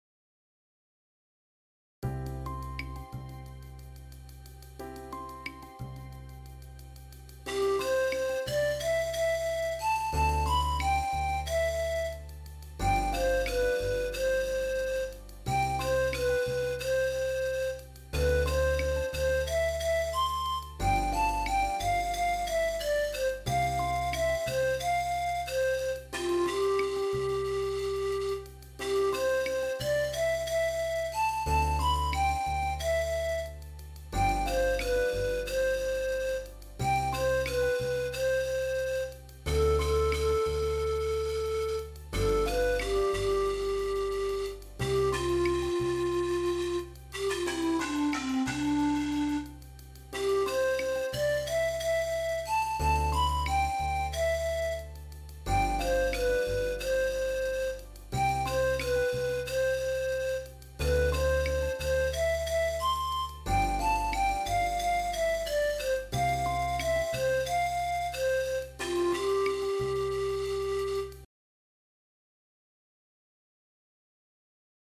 オリジナル(インスト)